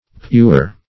Puer \Pu"er\, n. [Etymol. uncertain.]